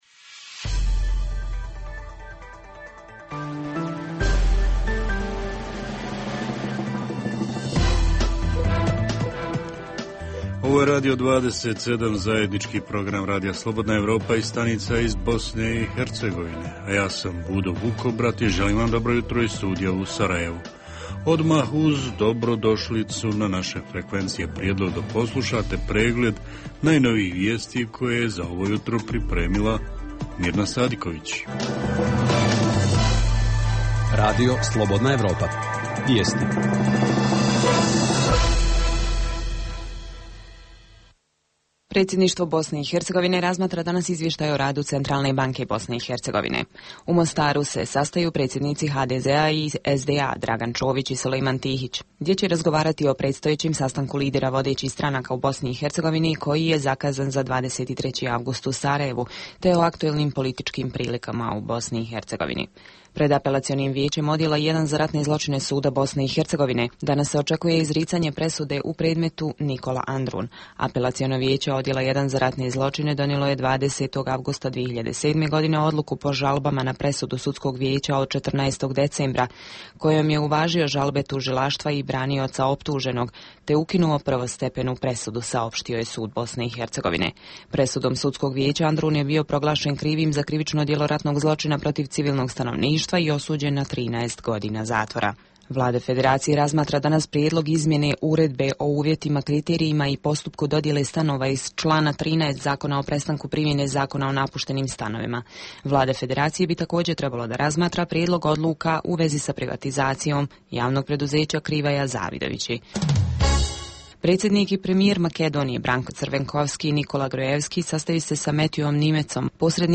Program za BiH koji se emituje uživo ovog jutra istražuje hoće li pojeftiniti udžbenici. Osim toga, reporteri iz cijele BiH javljaju o najaktuelnijim događajima u njihovim sredinama.
Redovni sadržaji jutarnjeg programa za BiH su i vijesti i muzika.